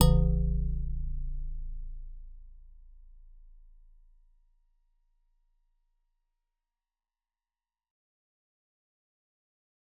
G_Musicbox-A0-f.wav